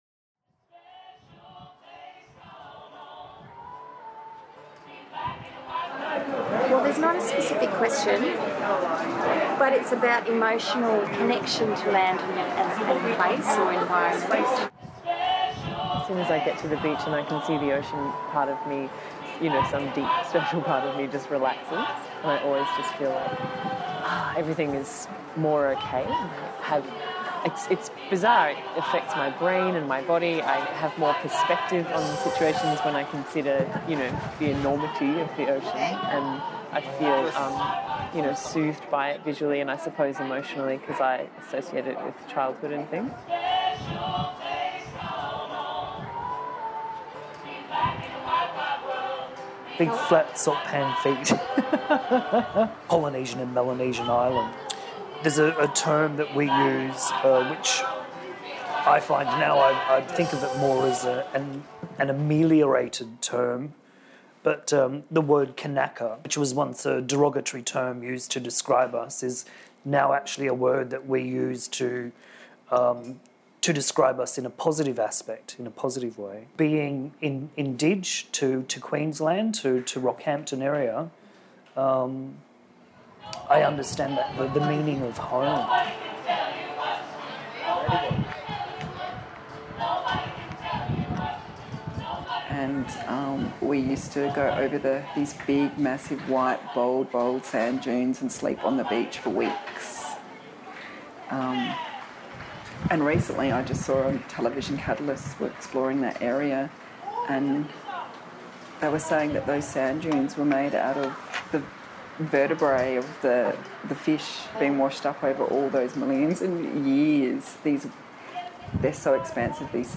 Recordings from Wominjeka Festival, Footscray Community Arts Centre 2014